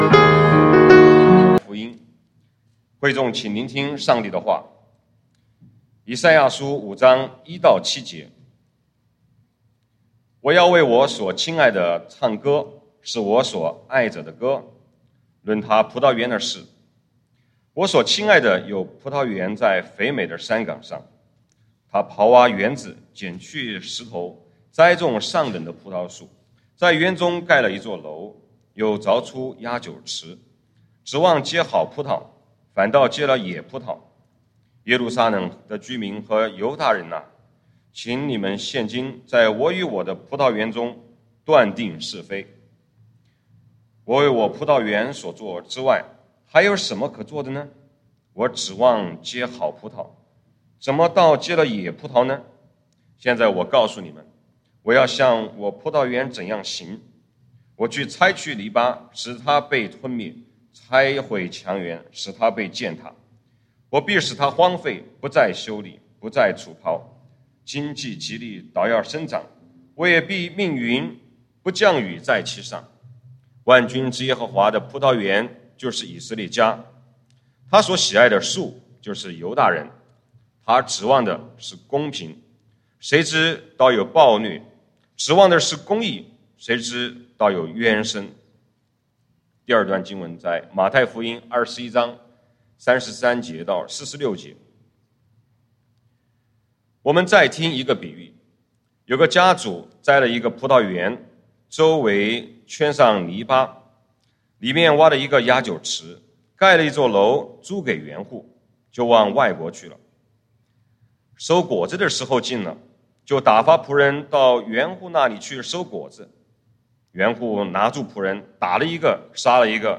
講道經文：《以賽亞書》Isaiah 5:1-7 和《馬太福音》Matthew 21:33-46